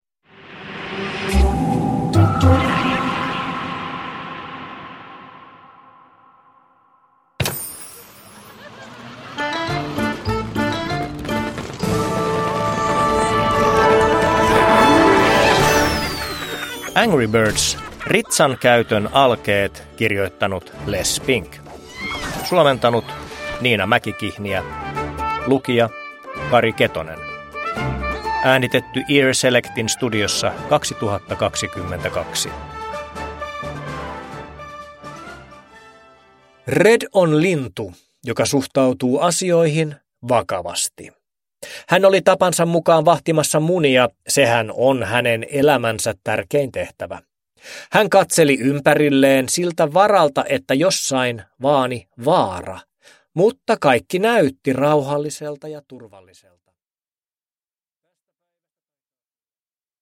Angry Birds: Ritsan käytön alkeet – Ljudbok – Laddas ner